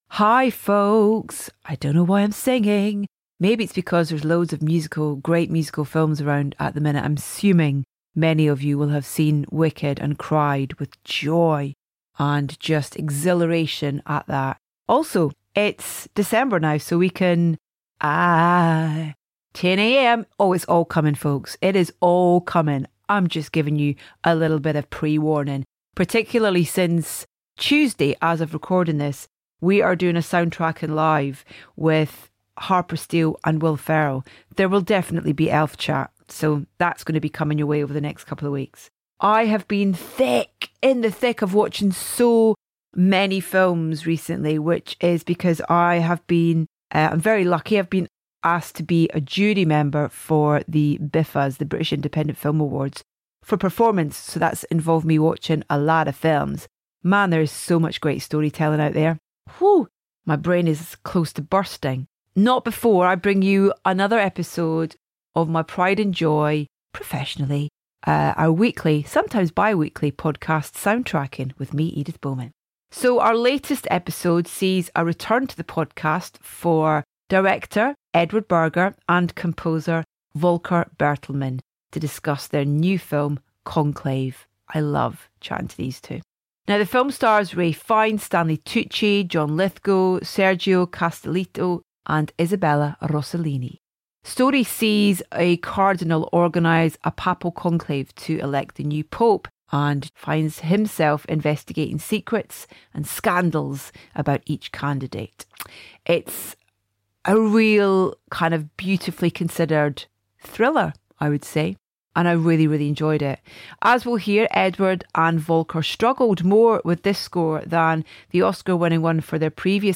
Our latest episode of Soundtracking sees a return to the podcast for director Edward Berger and composer Volker Bertelmann to discuss their new film, Conclave.